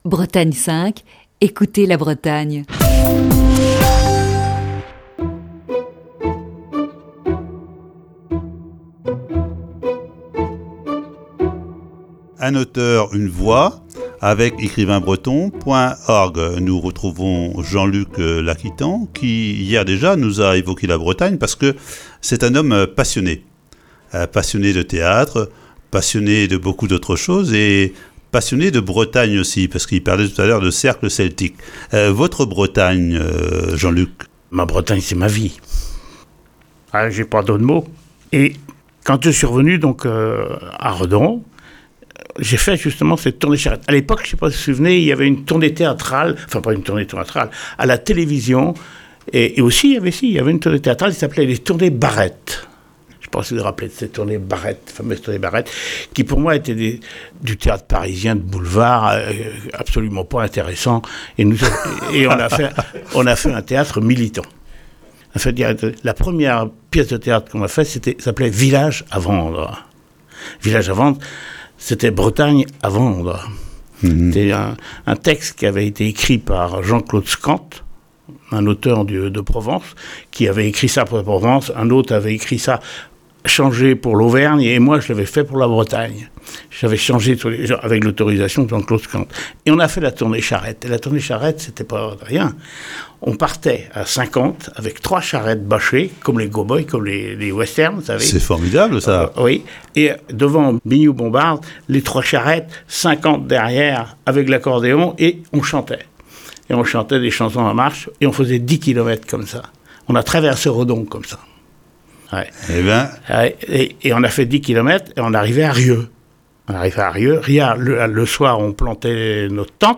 Ce matin, voici la deuxième partie de cet entretien.